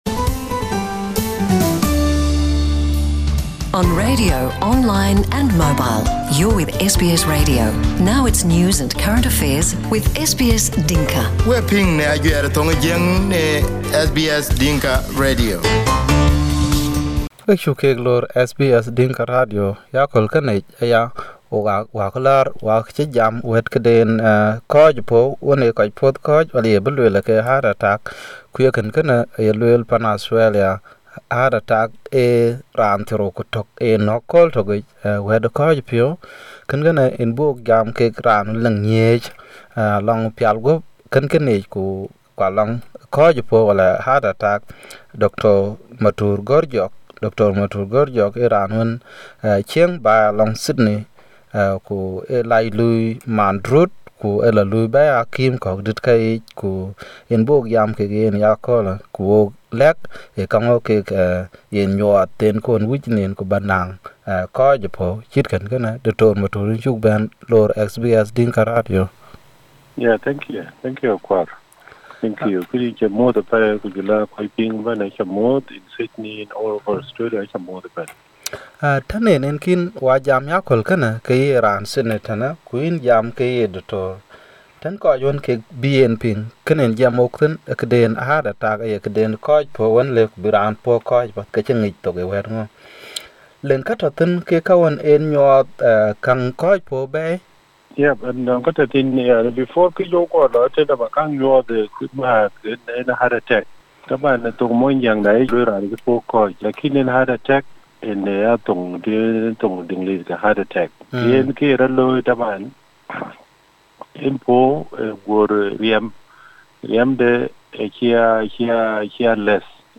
Heart attack signs and warning interview